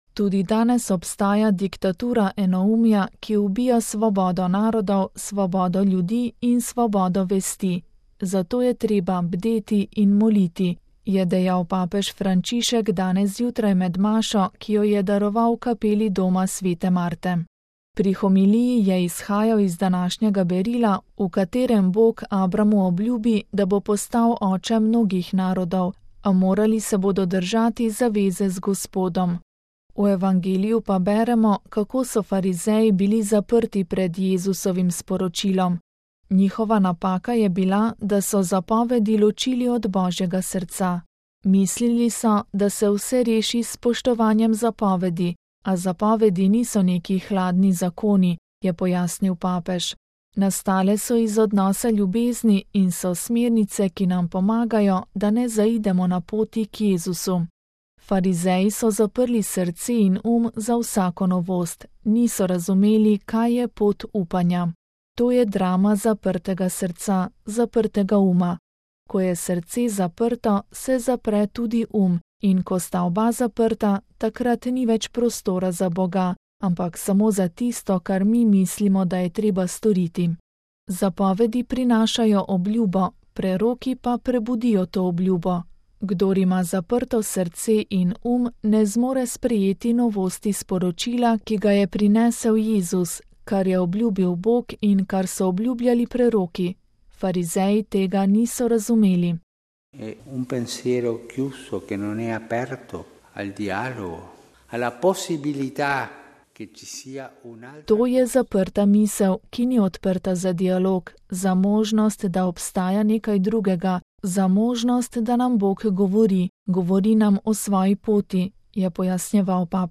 Frančišek med jutranjo mašo posvaril pred zaprtostjo srca in misli: Tudi danes obstaja diktatura enoumja